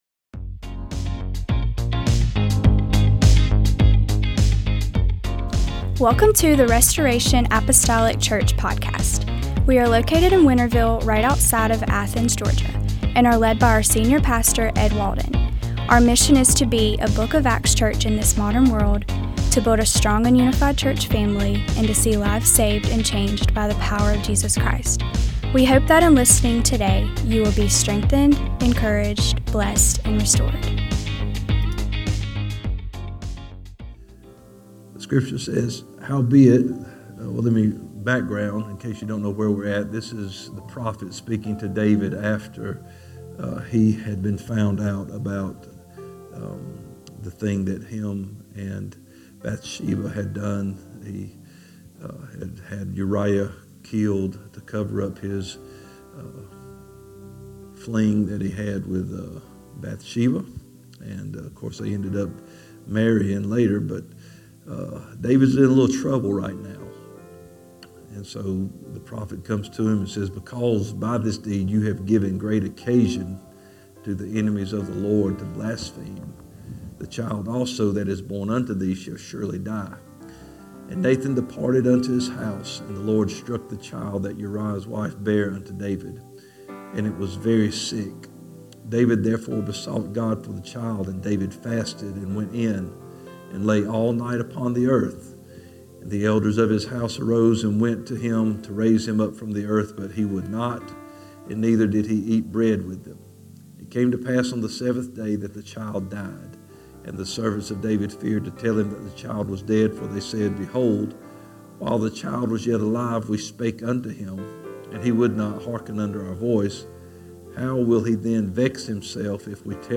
Sunday School 12/07/2025